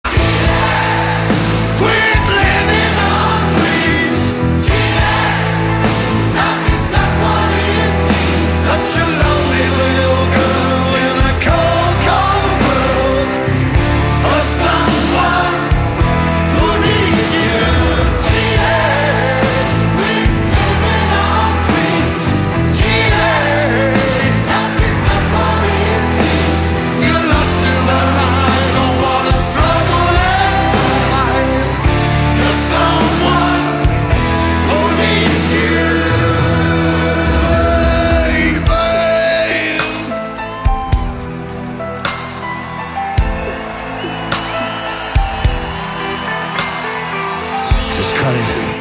• Качество: 128, Stereo
мужской вокал
эпичные
ретро
tribute